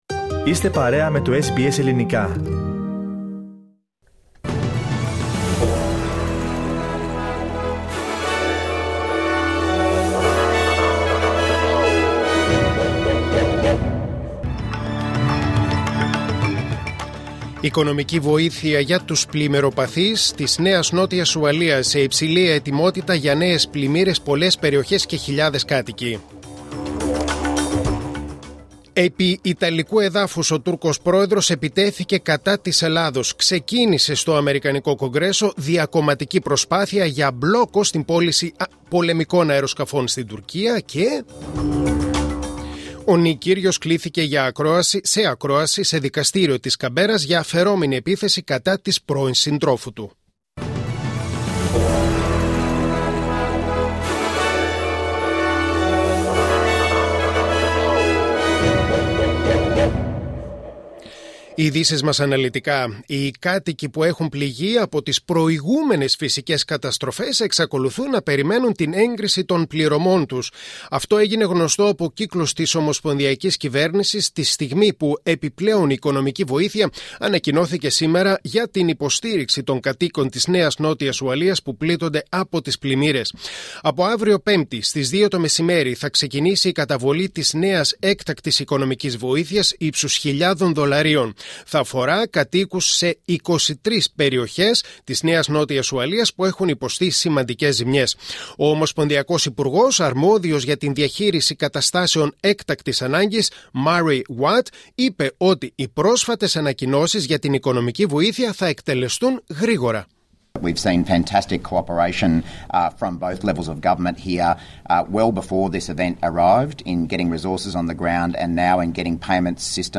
News in Greek: Wednesday 6.7.2022